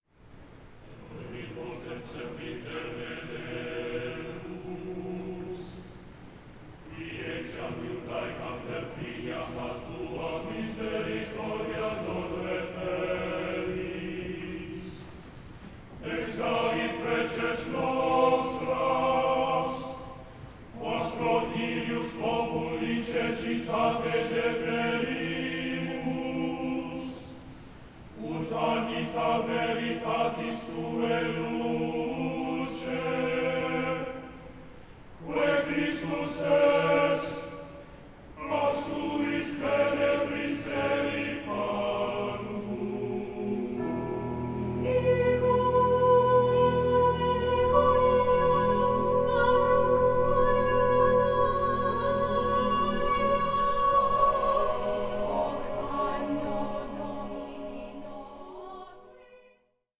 Aria narrativa